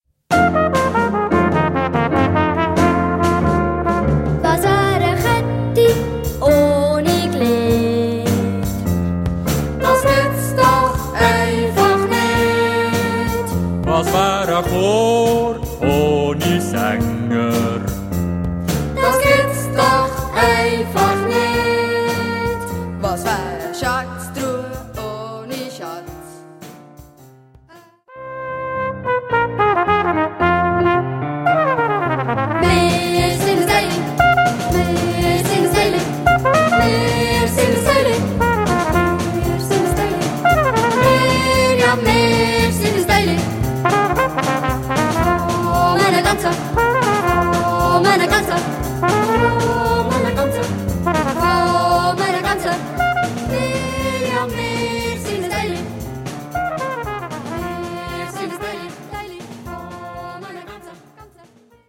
Chor, Flügelhorn, Drum Set, Klavier